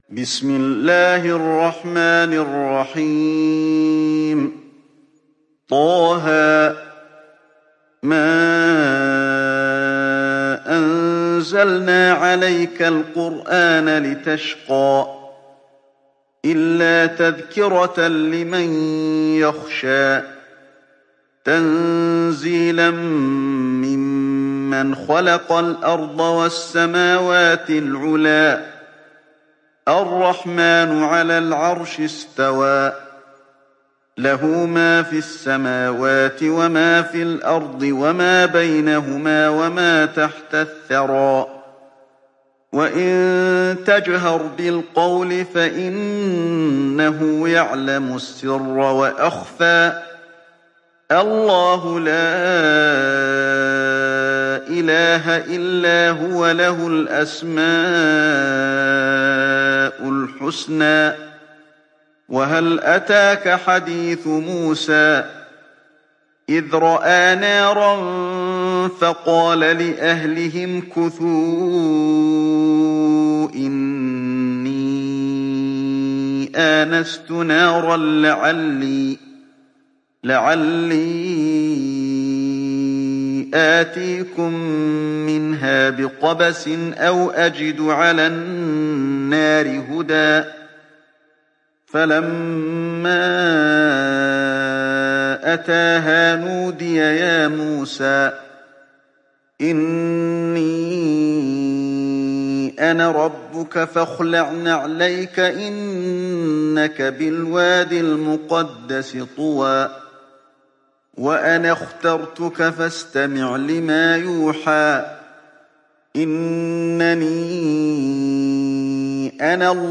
تحميل سورة طه mp3 بصوت علي الحذيفي برواية حفص عن عاصم, تحميل استماع القرآن الكريم على الجوال mp3 كاملا بروابط مباشرة وسريعة